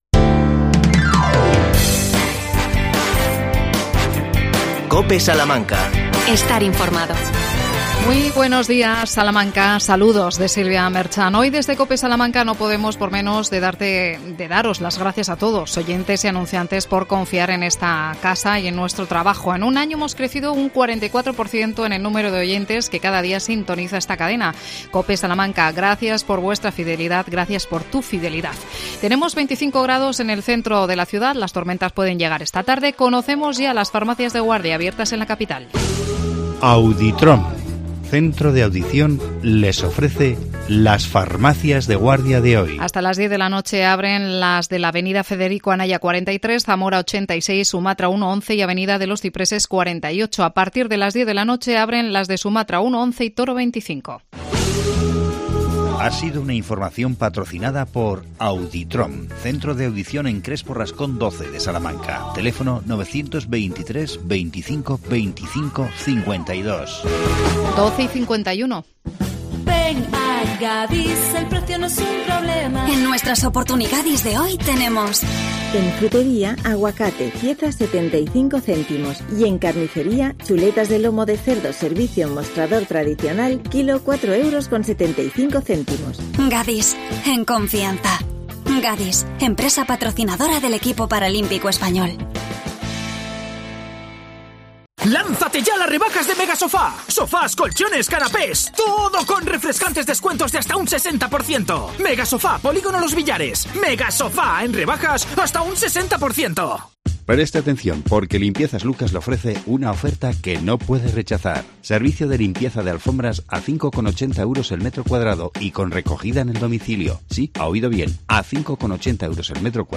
AUDIO: Entrevista a Miriam Rodríguez, concejala de Medio Ambiente. Explica los usos del nuevo contenedor marrón.